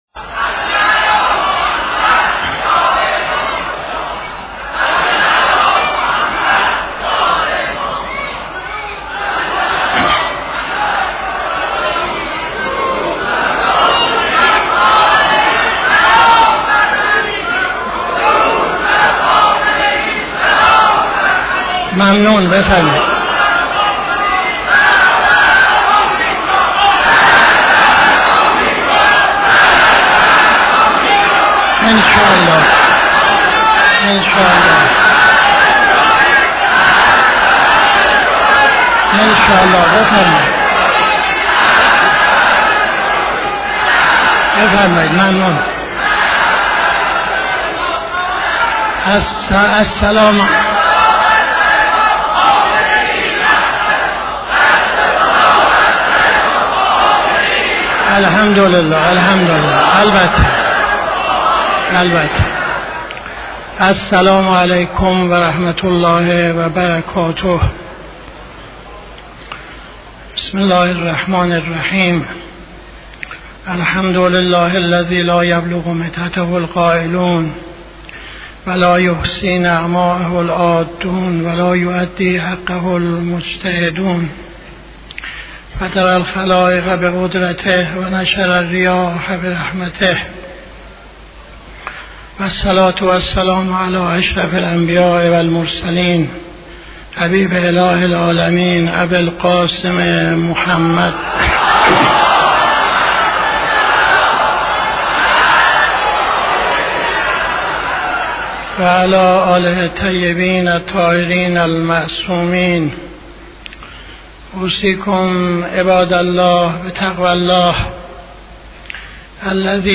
خطبه اول نماز جمعه 30-06-80